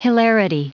Prononciation du mot : hilarity
hilarity.wav